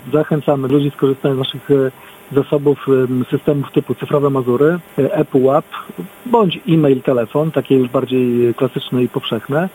– Pracujemy w trzech systemach – mówi o ograniczeniach dostępu do giżyckiego urzędu miasta burmistrz Wojciech Karol Iwaszkiewicz.